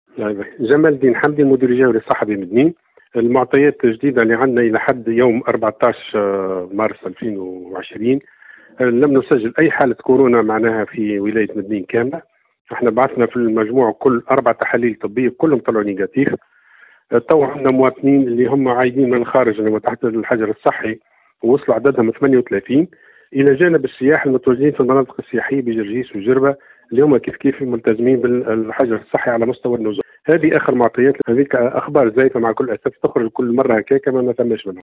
نفى اليوم الأحد المدير الجهوي للصحة بمدنين جمال حمدي في تصريح لمراسل "الجوهرة اف أم" بالجهة ما راج من أخبار حول وجود حالة اصابة بفيروس كورونا بولاية مدنين لدى مواطن عائد من الخارج.